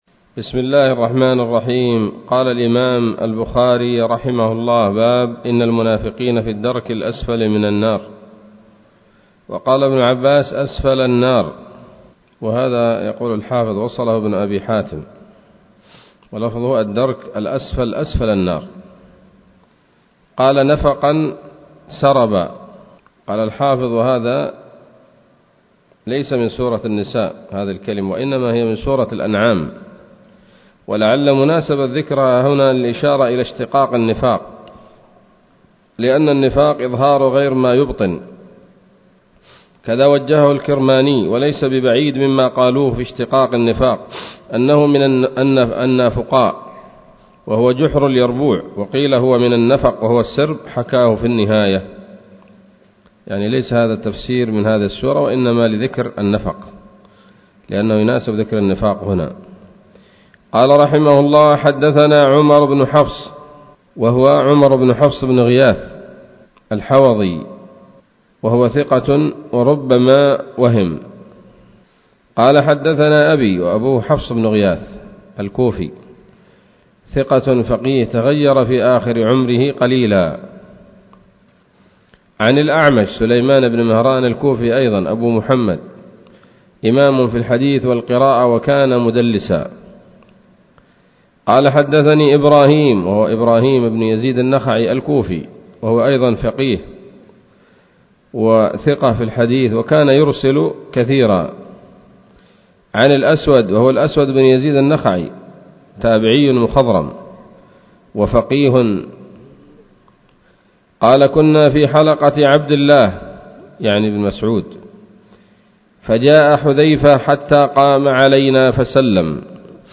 الدرس الثالث والثمانون من كتاب التفسير من صحيح الإمام البخاري